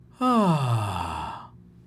Male Ahhhh
Category 😂 Memes
aah Ahh relax relief sound effect free sound royalty free Memes